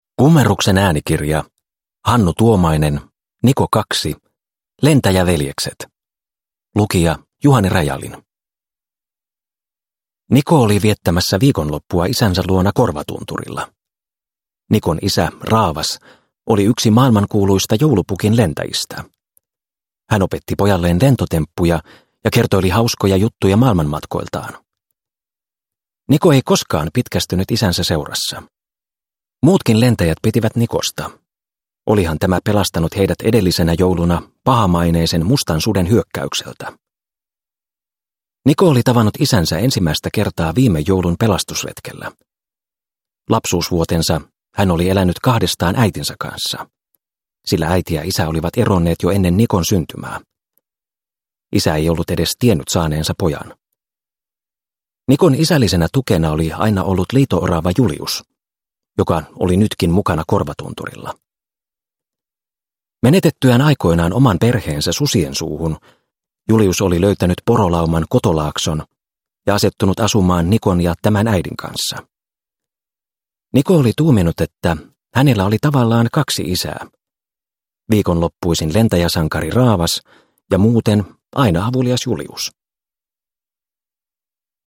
Niko 2 – Ljudbok – Laddas ner